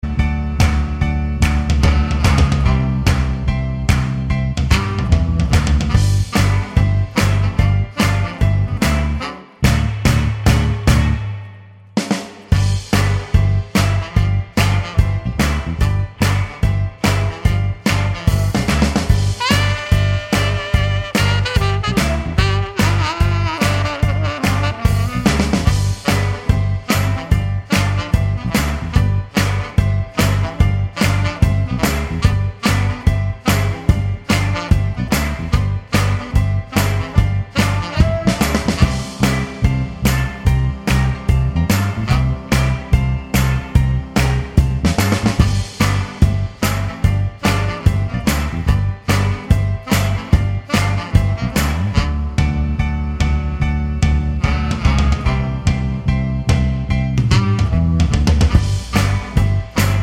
no Backing Vocals Rock 'n' Roll 3:04 Buy £1.50